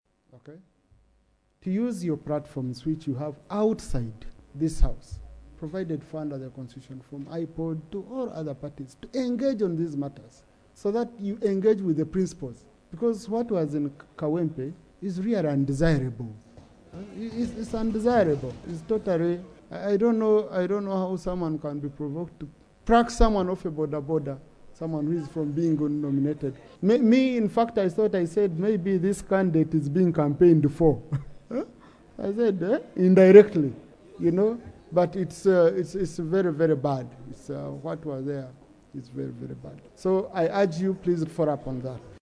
Deputy Speaker Thomas Tayebwa called for a thorough investigation into the conduct of the security forces.
AUDIO Deputy Speaker Tayebwa